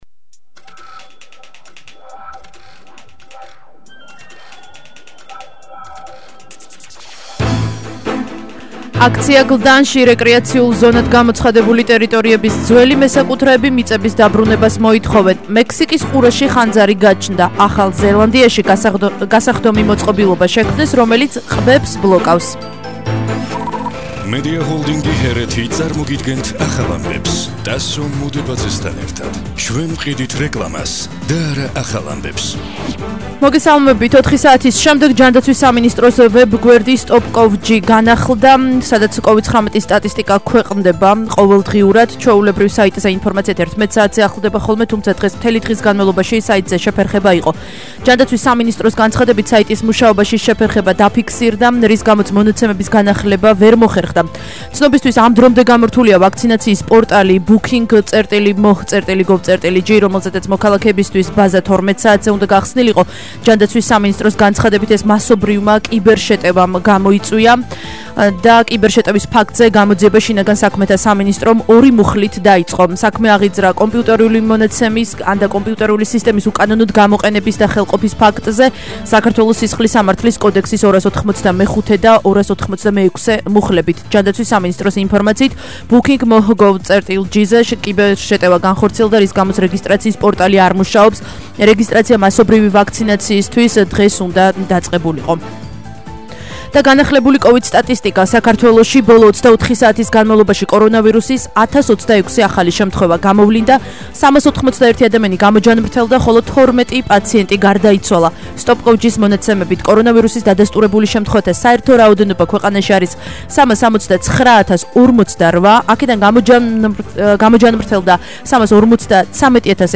ახალი ამბები 16:00 საათზე –03/07/21